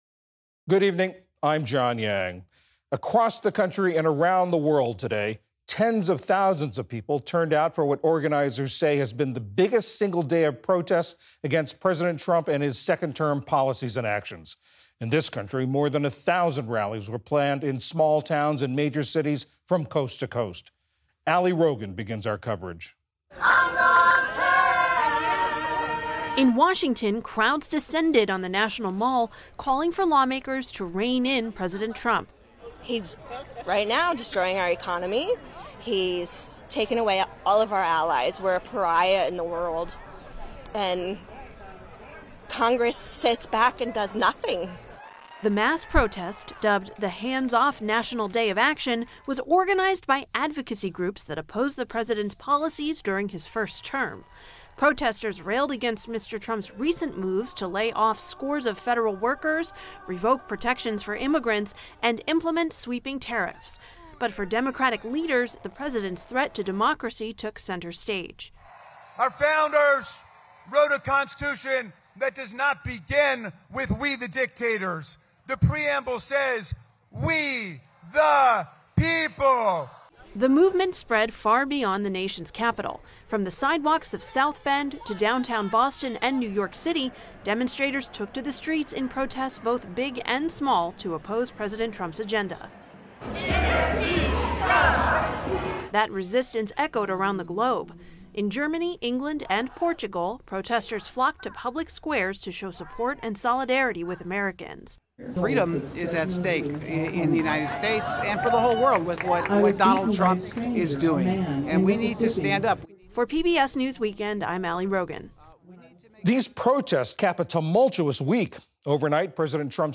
News, Daily News